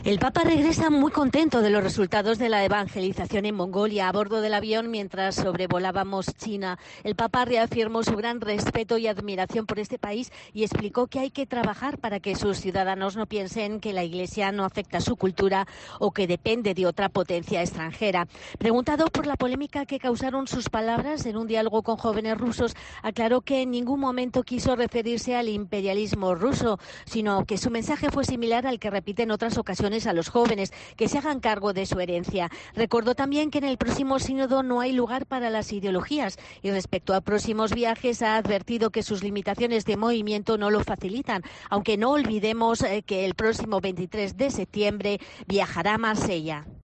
Durante la rueda de prensa a bordo del avión de regreso a Roma, Francisco ha valorado el viaje a Mongolia y apunta que en el Sínodo de octubre no habrá...